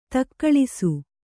♪ takkaḷisu